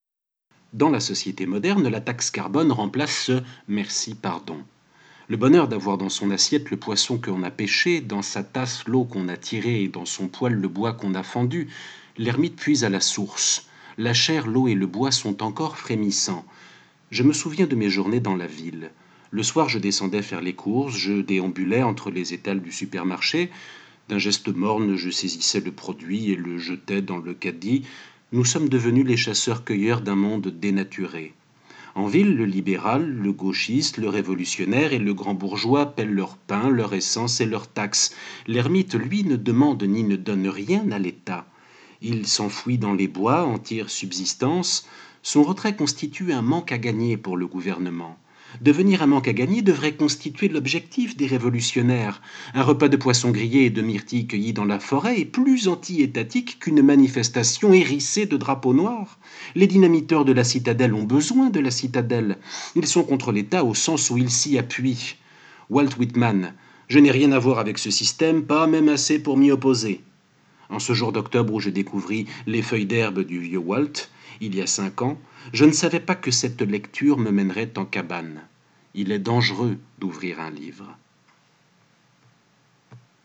Pour l’occasion, et parce que j’ai vraiment aimé ce que j’ai lu, plutôt que juste en reproduire les photos j’ai choisi de vous lire les pages en question :